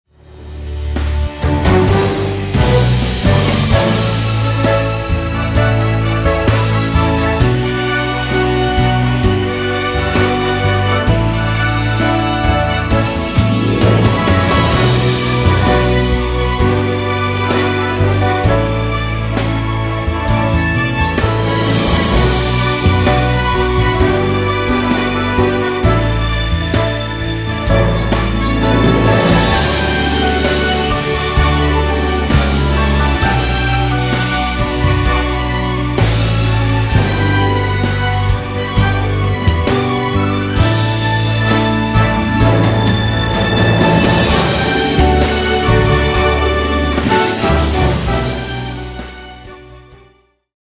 Pop , Musical
Instrumental
backing track